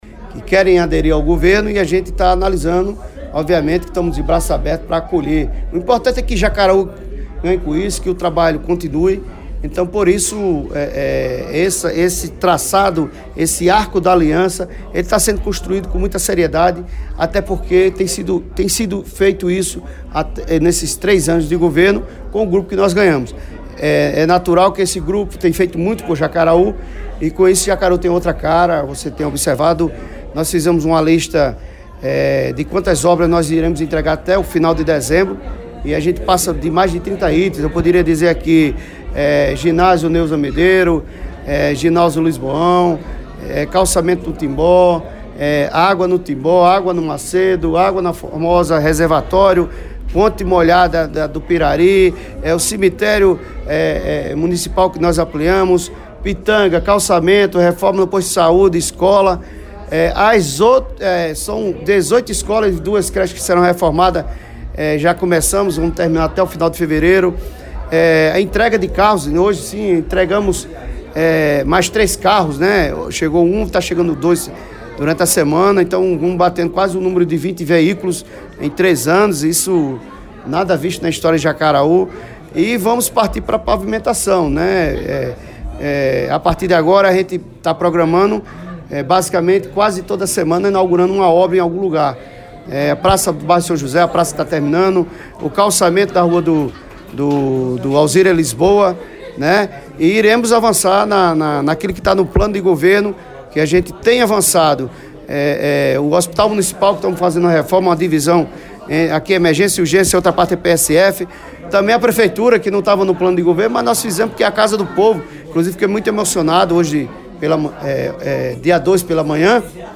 Durante entrevista ao PBVale e ao Programa Panorama 92 (92,3 fm Mamanguape), o gestor destacou avanços administrativos de sua gestão desde o primeiro ano de seu mandato (2017), e lembrou que hoje, Jacaraú vive novos tempos.